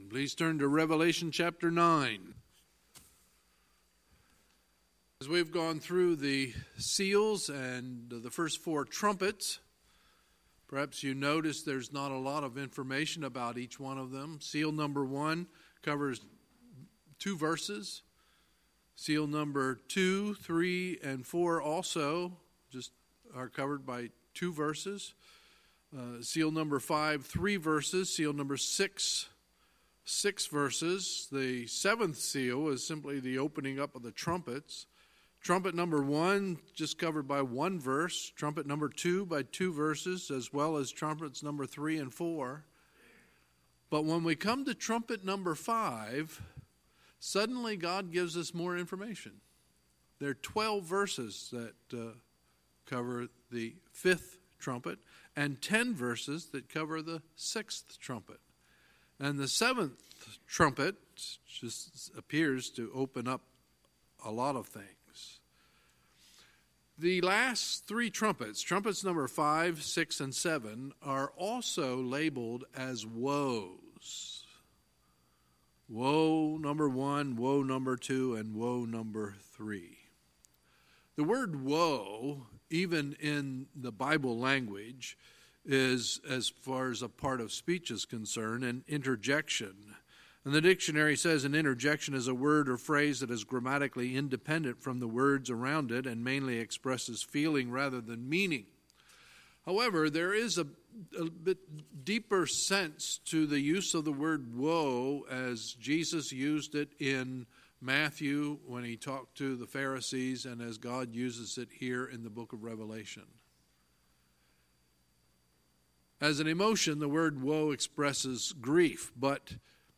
Sunday, February 3, 2019 – Sunday Evening Service